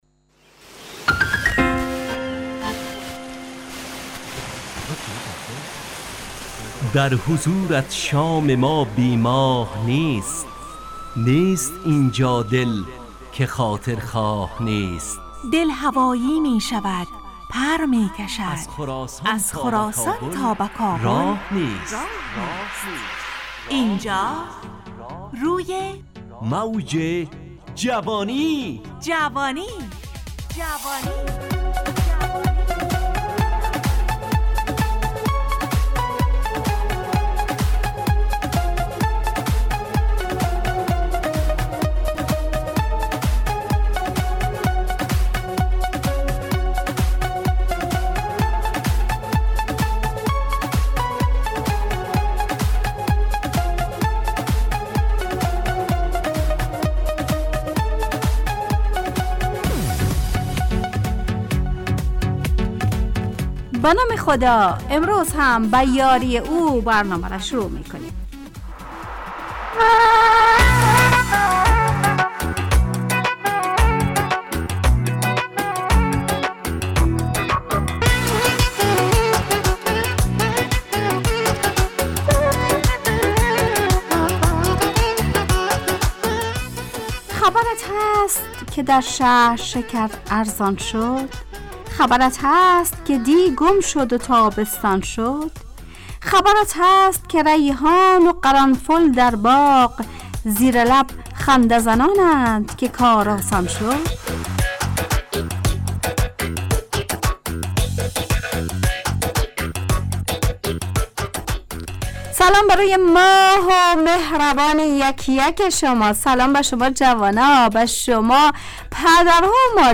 همراه با ترانه و موسیقی مدت برنامه 70 دقیقه . بحث محوری این هفته (خبر) تهیه کننده